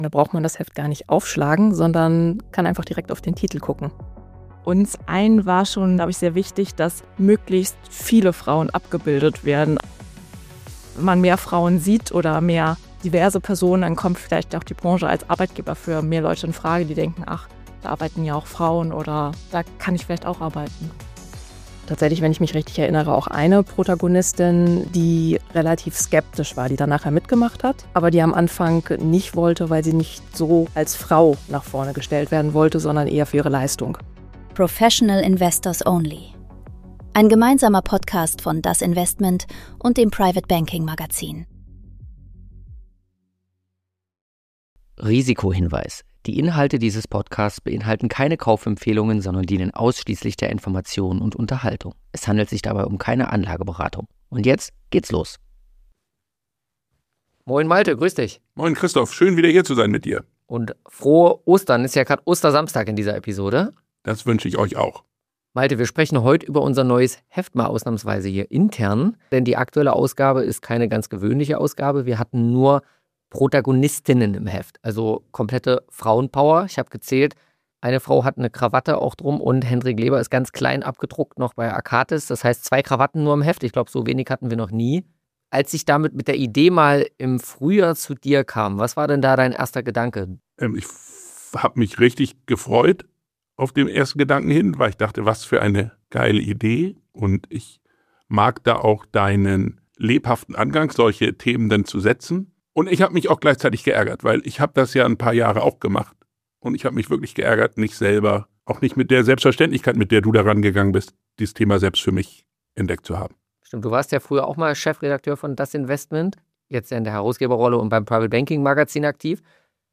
Ein Gespräch über Journalismus, Branchenkultur und die Frage, ob man sowas vor zehn Jahren auch schon hätte machen können.